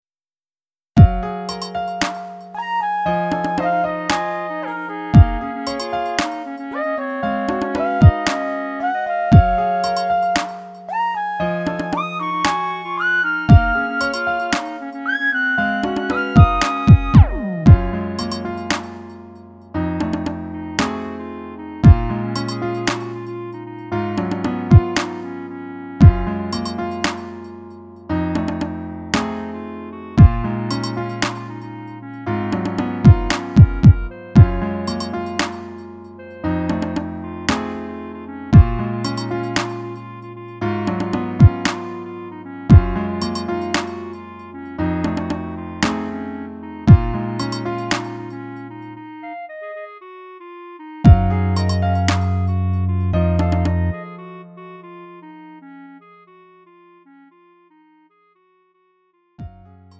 장르 가요 구분 Lite MR